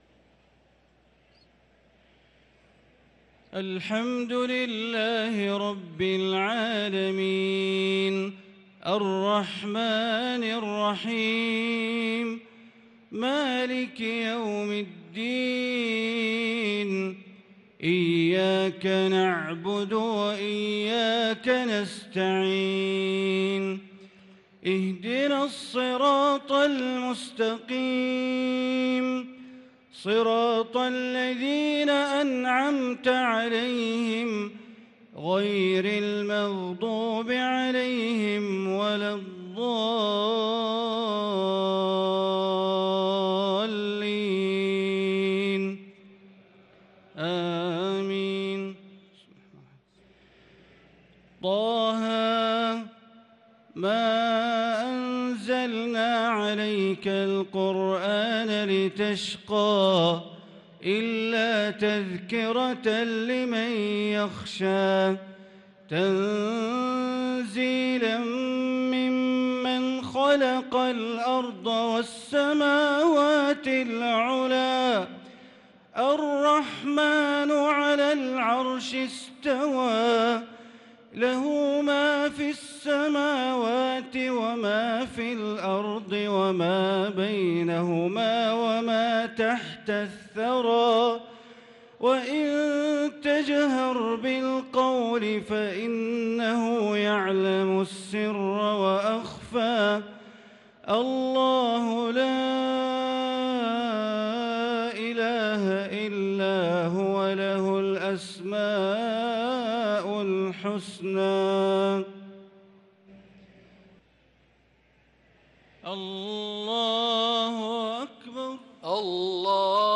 صلاة العشاء للقارئ بندر بليلة 26 رمضان 1443 هـ